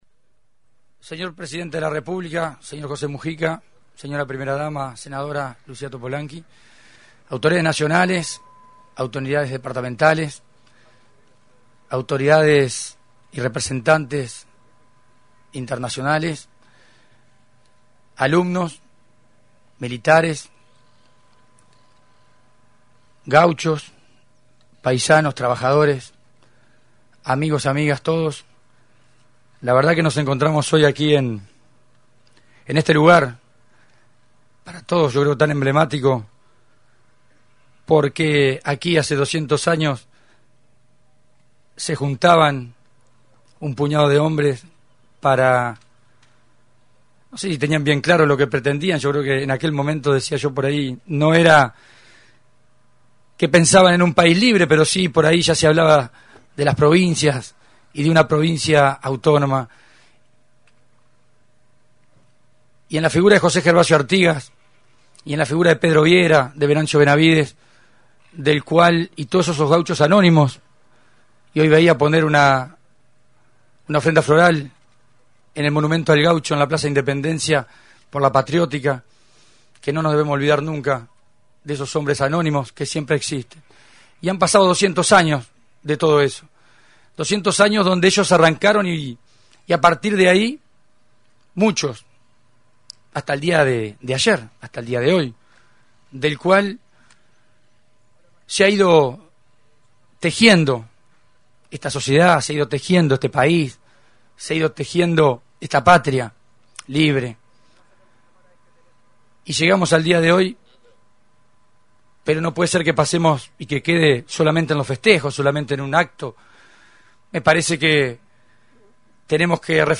Palabras del Ministro de Educaci�n y Cultura, Ricardo Ehrlich y el Intendente de Soriano, Guillermo Besozzi, en la celebraci�n del Bicentenario de la Revoluci�n Oriental. 14 min. 19 seg.